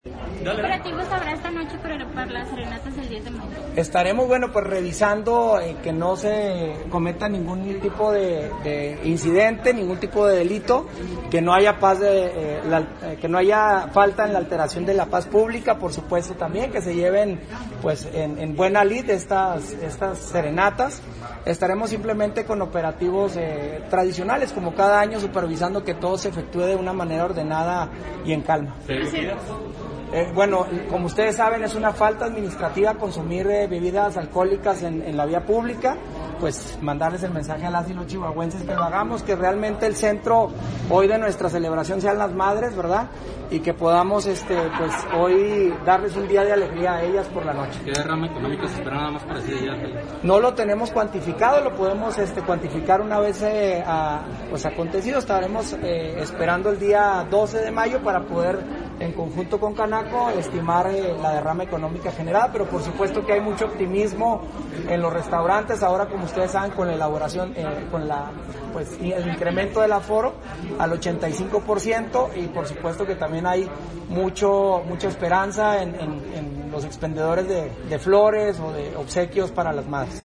Chihuahua, Chih.- El presidente municipal, Marco Antonio Bonilla Mendoza, pidió a la población que quien acuda a dar serenata a las mamás, debe hacerlo sin alterar el orden público para que la celebración se realice de la mejor manera.